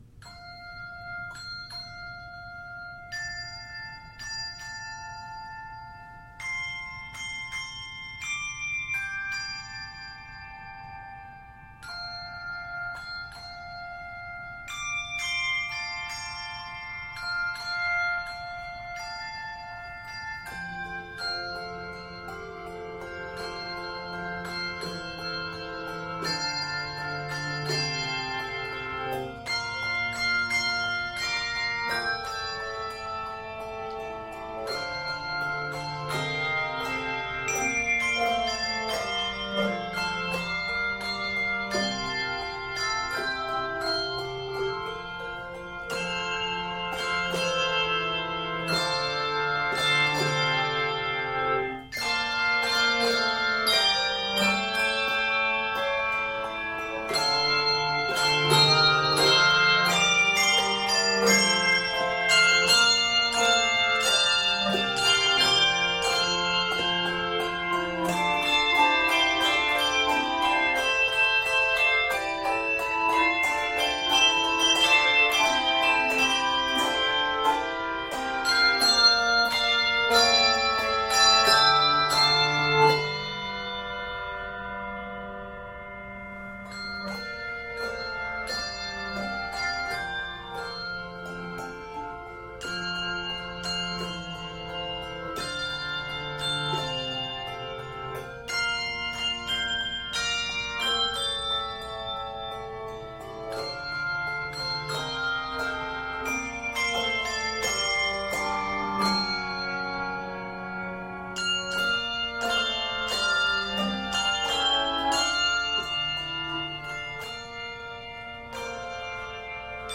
handbells
Set in F Major, this piece is 44 measures.